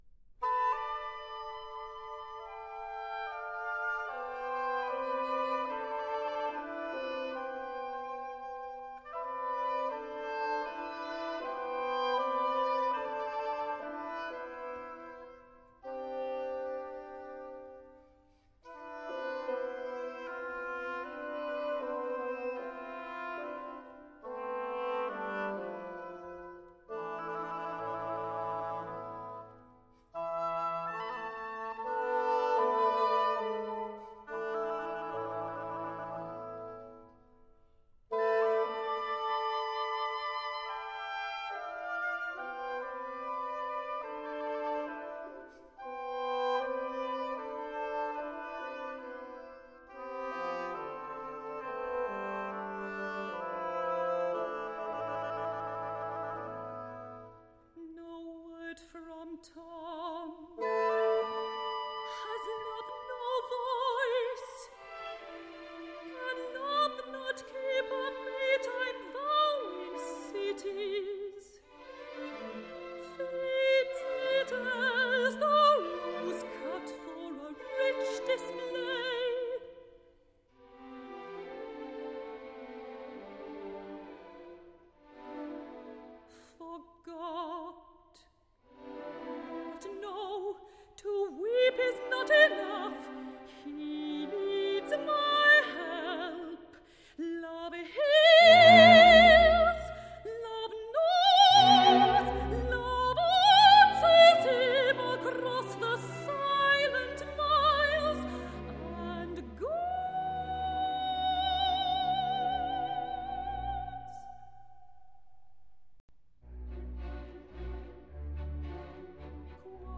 soprano
l’escena està estructurada en recitatiu